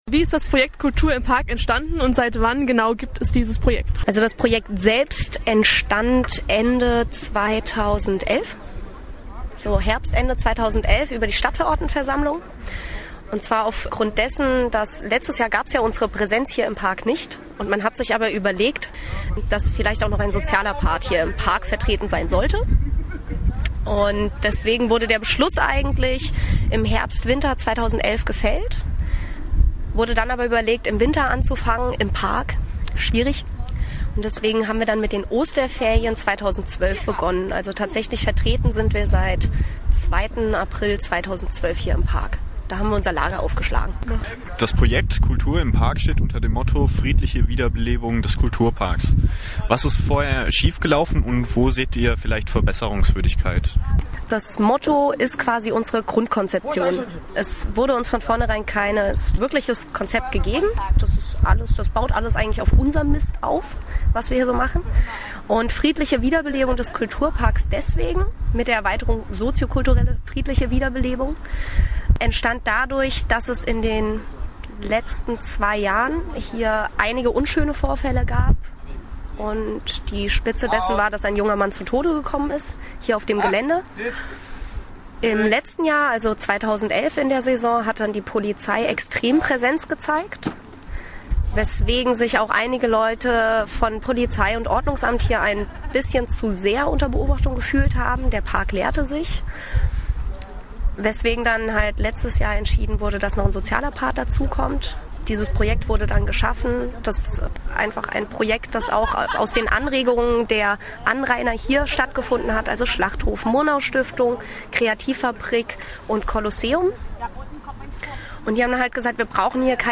Radio-Interview vom 20.09.2012 (MP3)